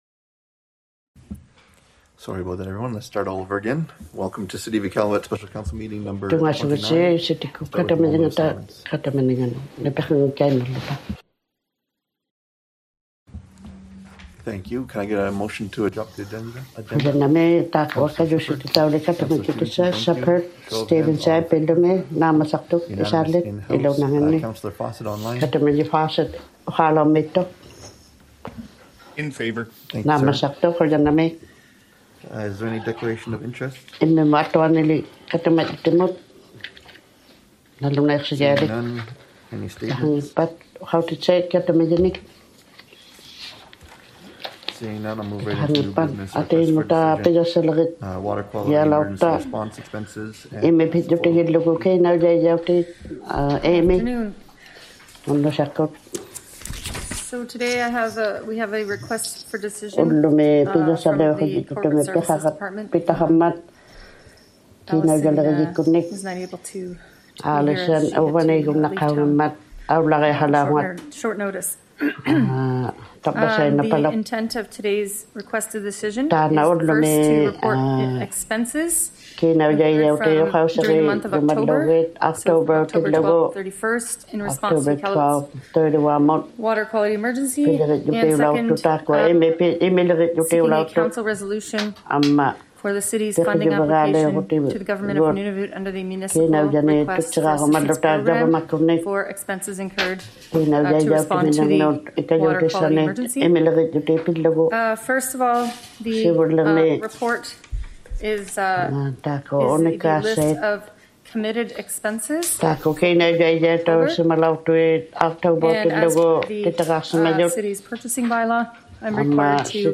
ᑕᒪᐅᓇᑐᐃᓐᓇᖅ ᓄᓇᓕᐸᐅᔭᒃᑯᑦᑦ ᑲᑎᒪᔨᖕᒋᑦᑕ ᑲᑎᒪᓂᖕᒐᑦ #29 - Special City Council Meeting # 29 | City of Iqaluit